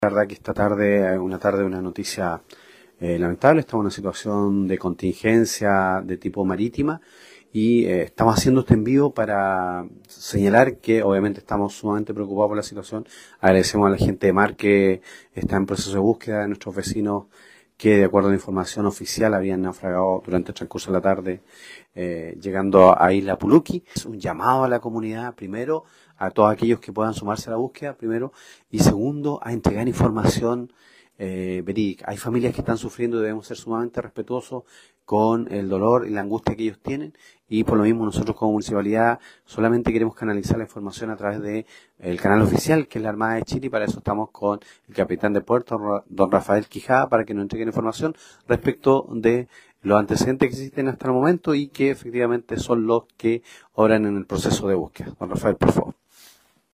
El alcalde de la comuna de Calbuco, Juan Francisco Calbucoy, se sumó al respaldo para la búsqueda de los desaparecidos, solicitando que embarcaciones artesanales se integren a los operativos.